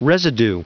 Prononciation du mot residue en anglais (fichier audio)
Prononciation du mot : residue